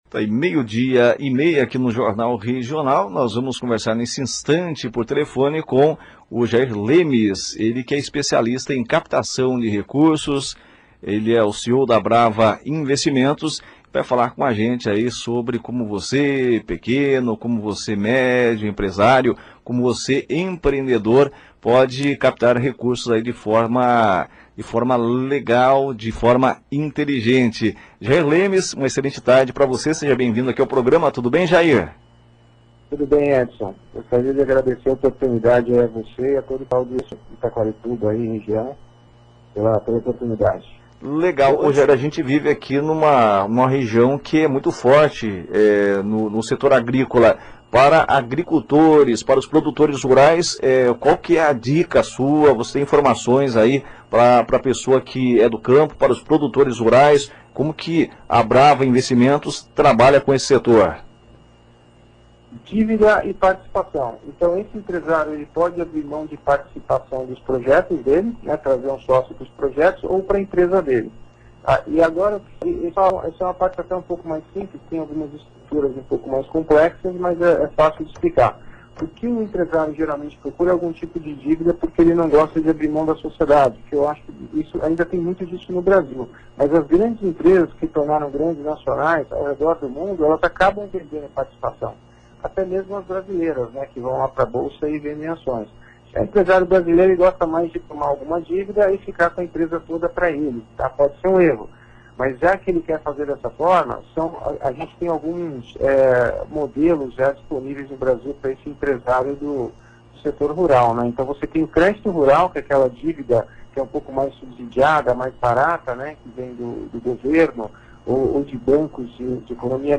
Entrevista dada ao Jornal Regional • Captação de Recursos para o Setor Agrícola